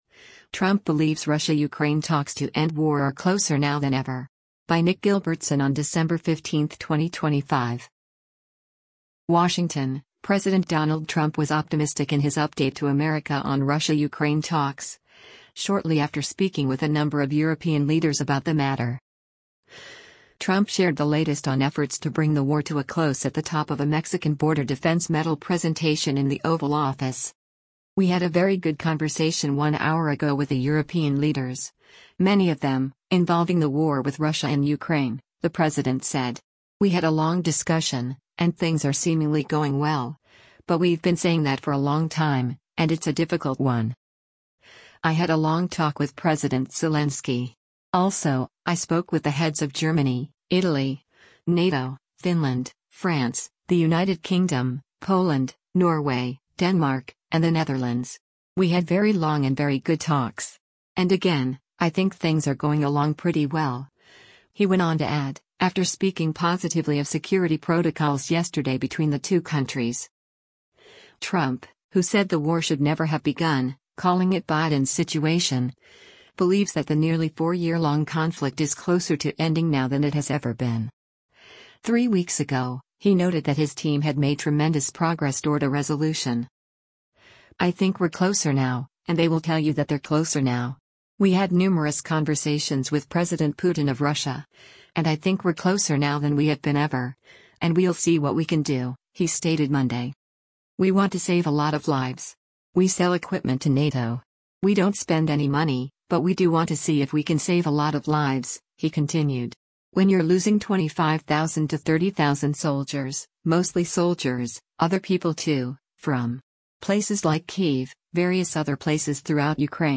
Trump shared the latest on efforts to bring the war to a close at the top of a Mexican Border Defense Medal Presentation in the Oval Office.